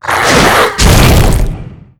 monsterpunch.wav